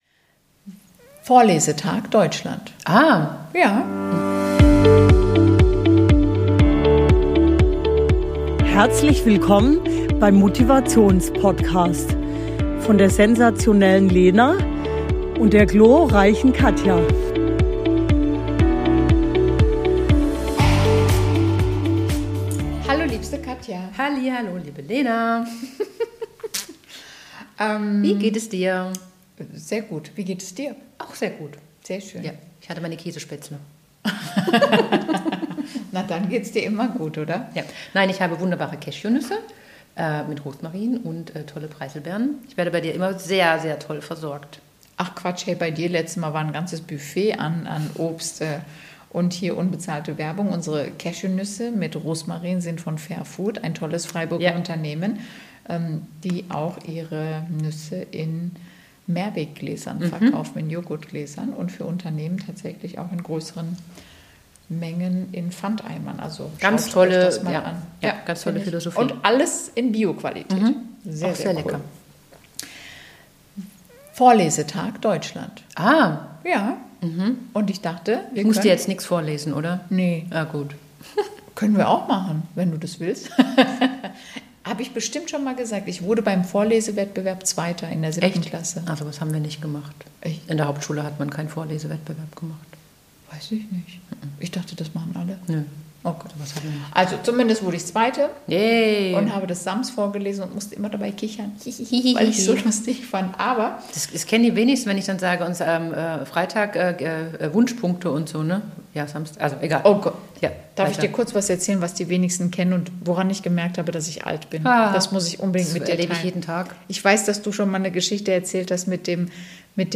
Ein Gespräch über das Lesen, das Leben – und das Älterwerden mit Humor.